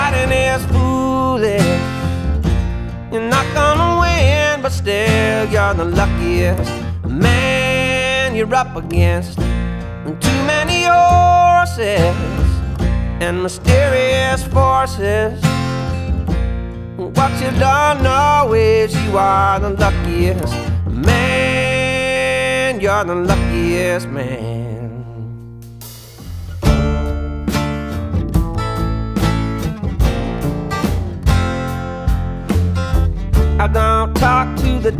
Жанр: Поп музыка / Альтернатива / Фолк / Кантри